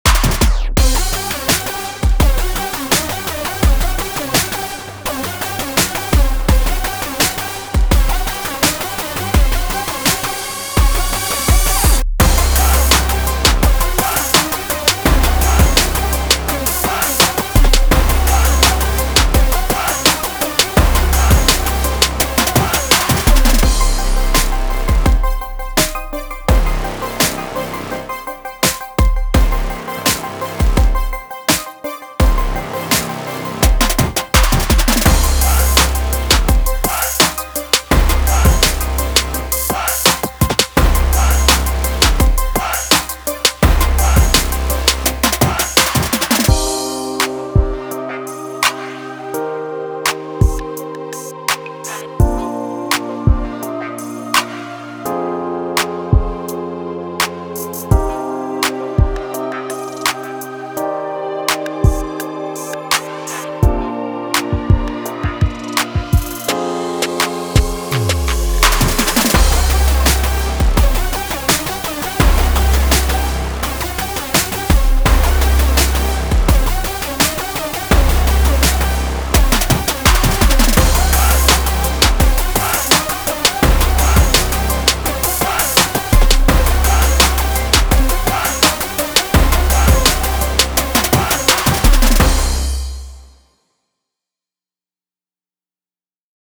Emotion: Uptempo, hyped, hi energy, sports, let’s go, fast